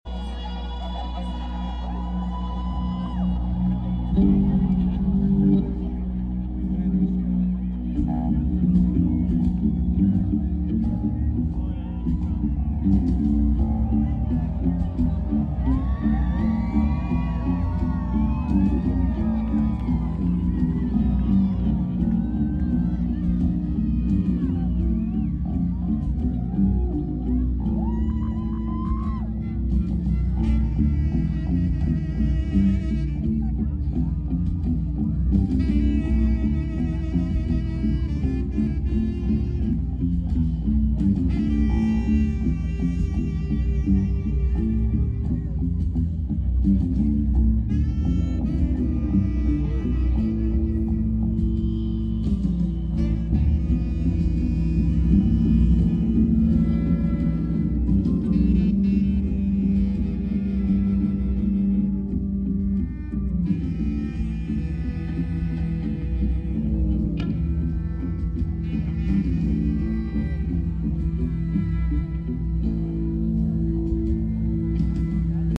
The eerie soundtrack of the sound effects free download
The eerie soundtrack of the 100th burning of Zozobra!